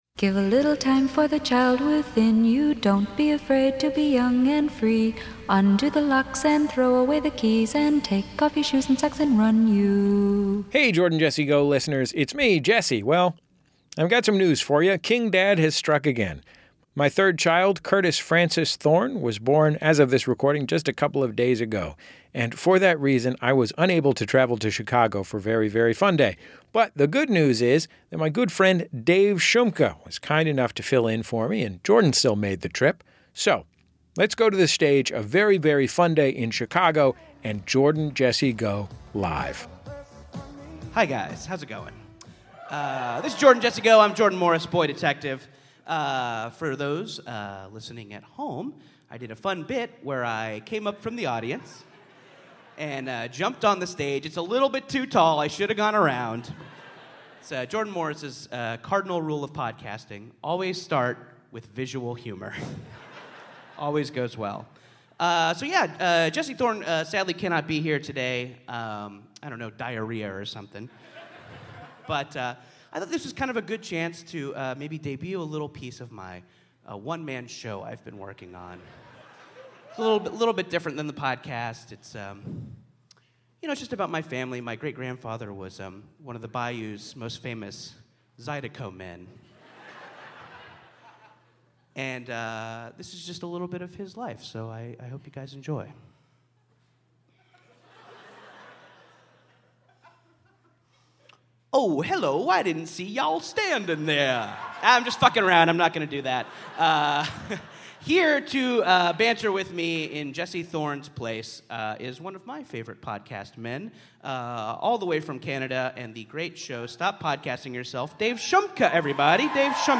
Live at Very Very Fun Day
Society & Culture, Comedy, Tv & Film